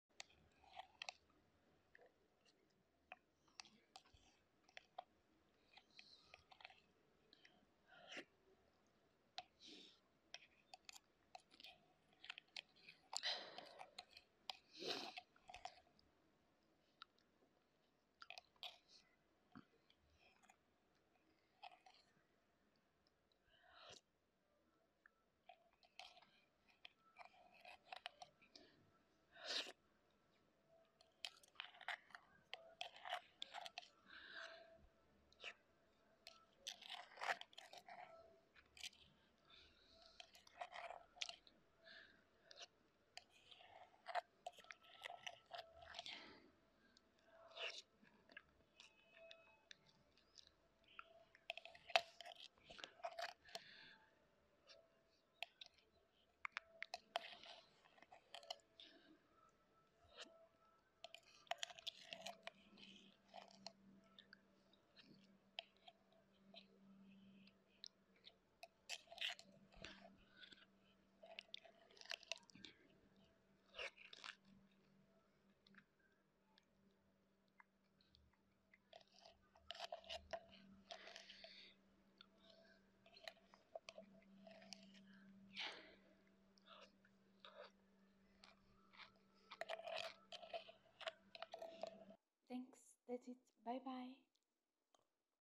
Mukbang Food ASMR😋😋